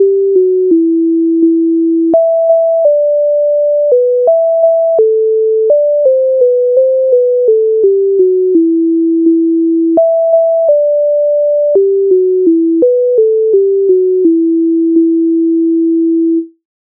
MIDI файл завантажено в тональності e-moll
Ой від саду Українська народна пісня з обробок Леонтовича с,130
Ukrainska_narodna_pisnia_Oj_vid_sadu.mp3